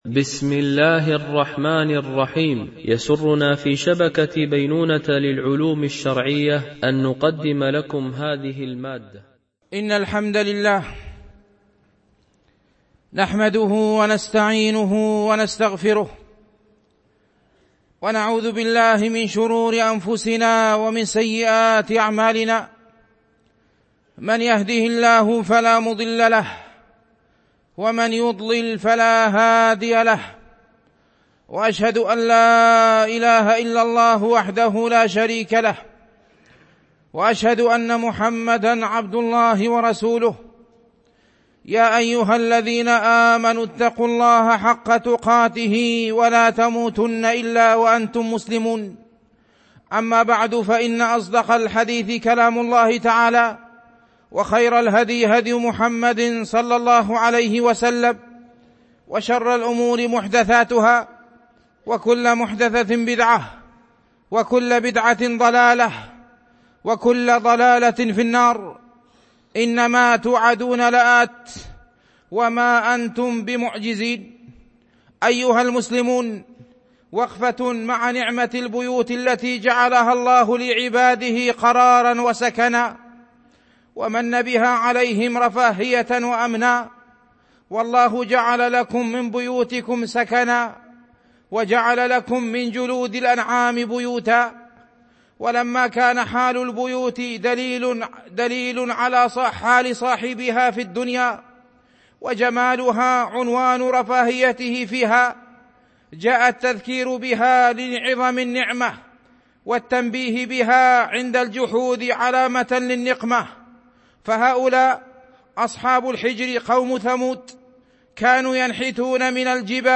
خطب